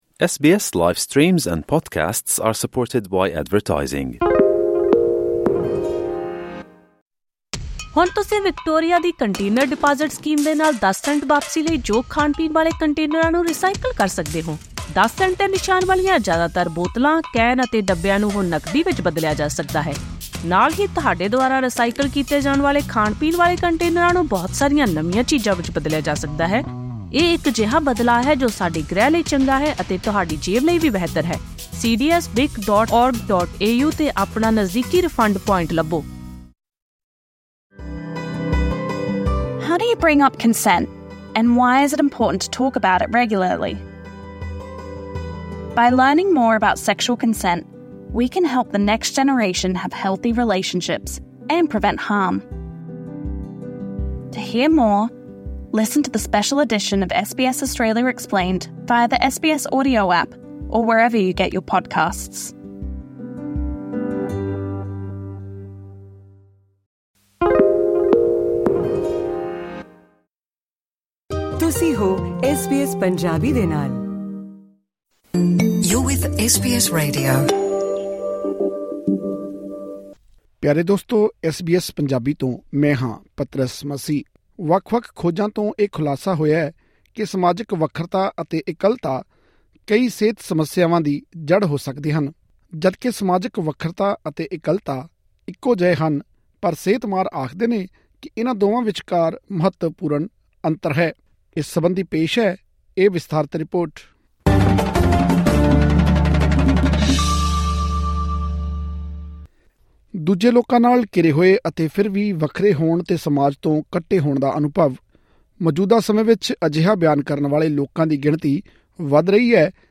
ਮਾਹਿਰਾਂ ਦਾ ਮੰਨਣਾ ਹੈ ਕਿ ਅਜਿਹਾ ਅਨੁਭਵ ਕਈ ਪ੍ਰਕਾਰ ਦੀਆਂ ਸਿਹਤ ਸਮੱਸਿਆਵਾਂ ਦਾ ਕਾਰਨ ਬਣਦਾ ਹੈ। ਵਿਸ਼ਵ ਸਿਹਤ ਸੰਗਠਨ ਦੀ ਰਿਪੋਰਟ ਦੱਸਦੀ ਹੈ ਕਿ ਵਡੇਰੀ ਉਮਰ ਦੇ ਲੋਕਾਂ ਵਿੱਚ ਡਿਪਰੈਸ਼ਨ, ਡਾਇਬਿਟੀਜ਼, ਦਿਮਾਗੀ ਕਮਜ਼ੋਰੀ ਆਦਿ ਸਮੱਸਿਆਵਾਂ ਦੇ ਵੱਧ ਰਹੇ ਮਾਮਲਿਆਂ ਪਿੱਛੇ ਸਮਾਜਿਕ ਇਕੱਲਤਾ ਨੂੰ ਨਜ਼ਰ ਅੰਦਾਜ਼ ਨਹੀਂ ਕੀਤਾ ਜਾ ਸਕਦਾ। ਹੋਰ ਵੇਰਵੇ ਲਈ ਸੁਣੋ ਇਹ ਰਿਪੋਰਟ